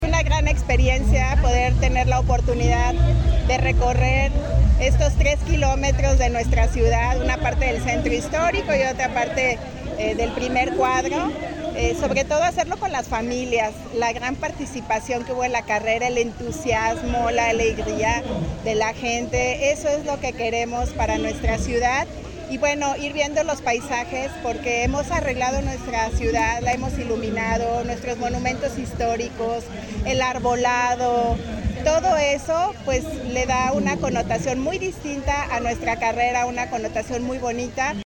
Karina Padilla, procuradora de la PAOT